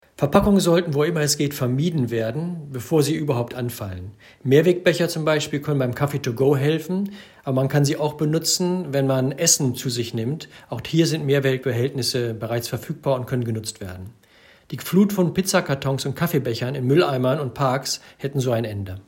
Dirk Messner zu den Verpackungsabfällen 2018 (O-Ton 1)